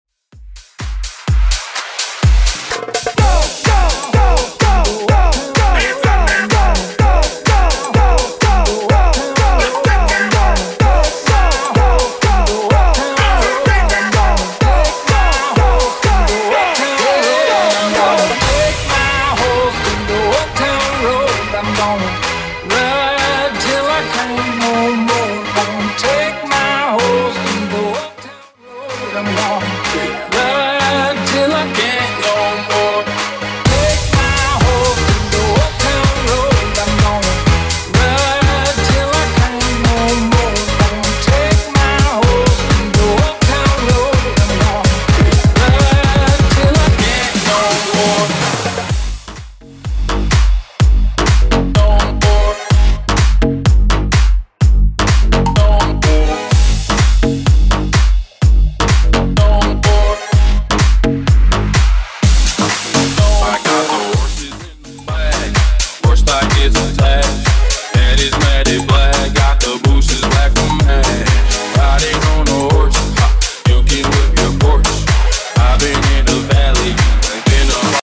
HIPHOP , PARTY BREAKS , TOP40
Clean & Dirty